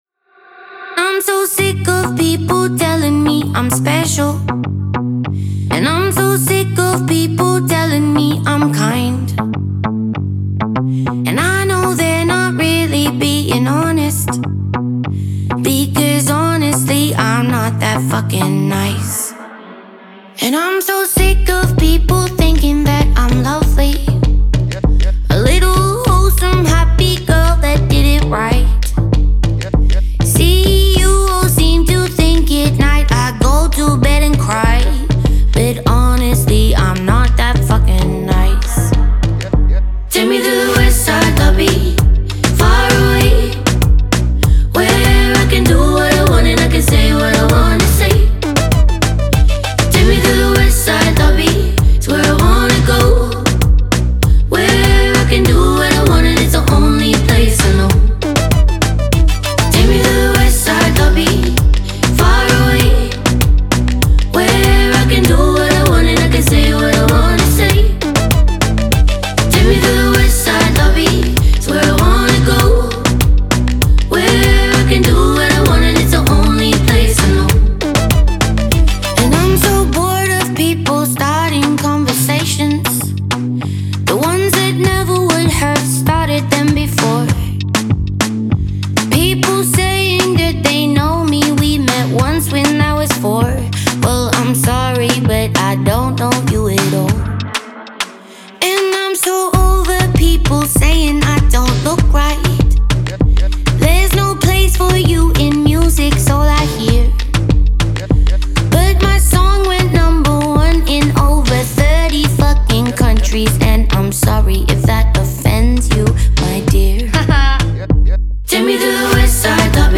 это яркая и энергичная песня австралийской певицы